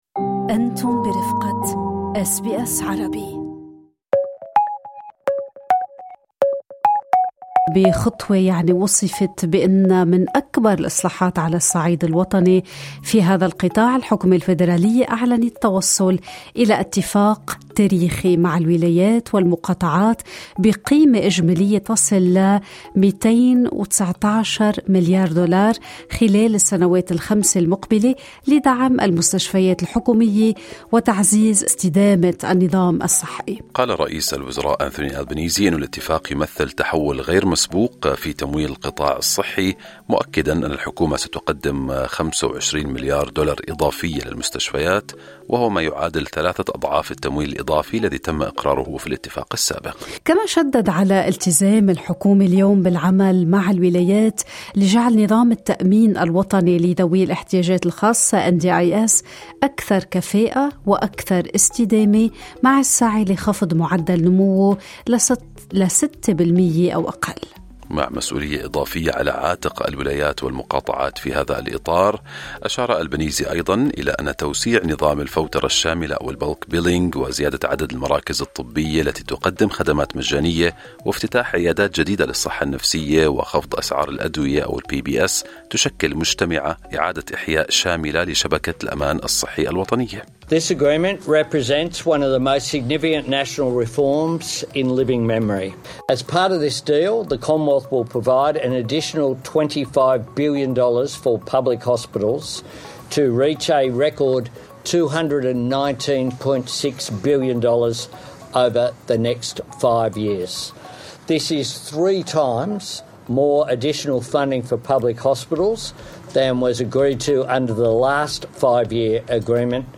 طبيب طوارئ يشرح "المشكلة الأكبر" في مستشفيات أستراليا